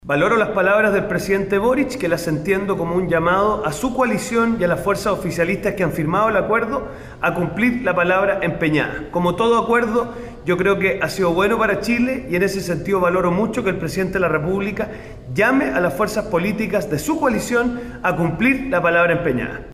El diputado y secretario general de RN, Diego Schalper, se unió a una serie de voces de oposición que respaldaron a Boric, apuntando a un “llamado de atención” a sus propias fuerzas.